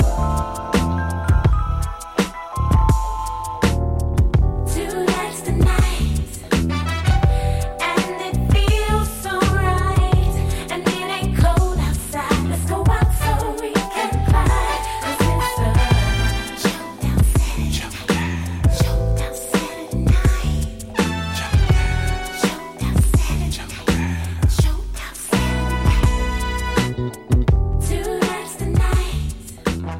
Funk / Jazz / Soul / Hip Hop